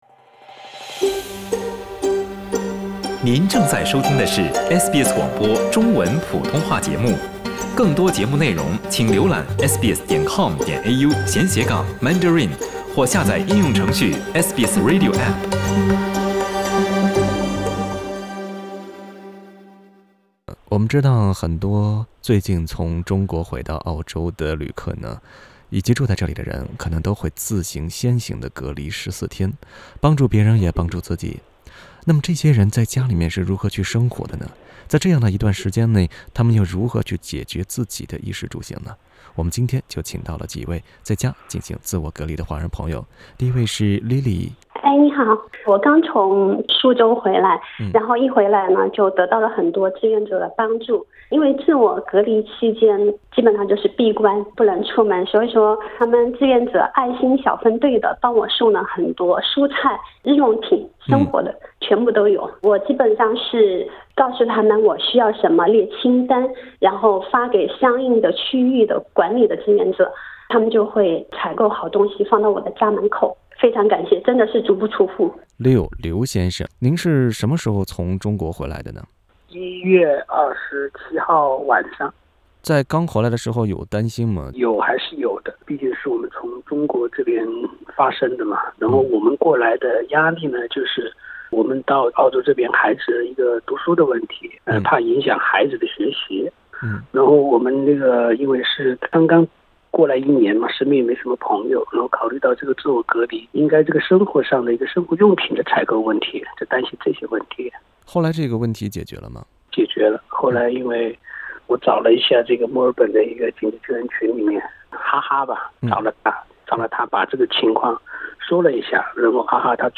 SBS 普通话电台